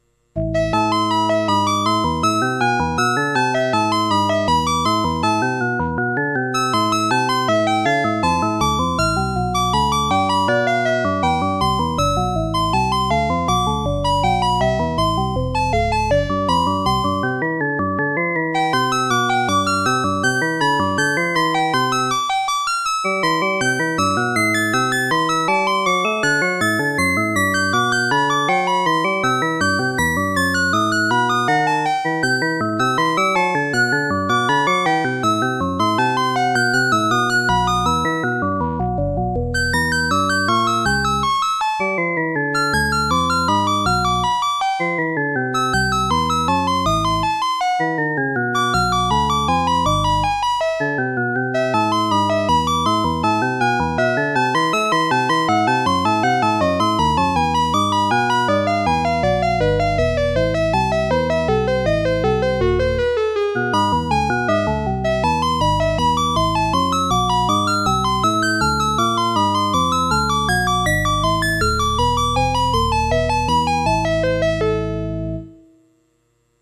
Sound quality is excellent using a 12-bit DAC, 32kHz sample rate and 32-bit precision DSP computations.
'Two-part Invention' (J.S. Bach)  - Duet for 2 Sigma6 synth's, played by a MIDI sequencer.